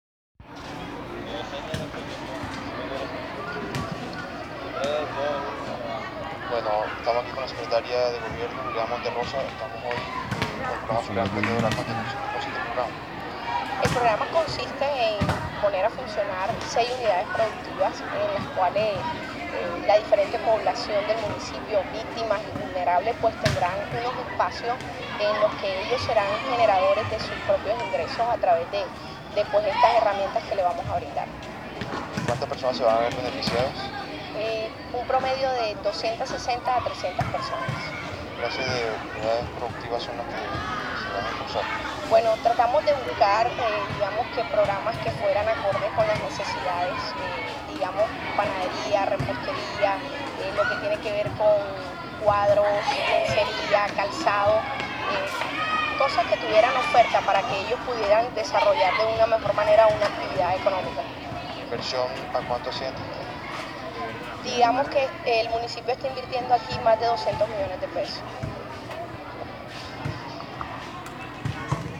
La secretaria de Gobierno de Soledad, Juliana Monterroza, explicó durante el lanzamiento del programa que el objetivo es poner a funcionar seis unidades productivas en lo referente a comida para eventos, bisutería, zapatería, repostería, decoración para carnaval y cuadros y elementos de decoración.
Soledad-Emprendedora-Juliana-Monterroza-Sec.-de-Gobierno.m4a